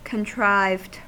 Ääntäminen
Synonyymit staged fictitious Ääntäminen US Tuntematon aksentti: IPA : /kənˈtraɪvɪd/ Haettu sana löytyi näillä lähdekielillä: englanti Contrived on sanan contrive partisiipin perfekti.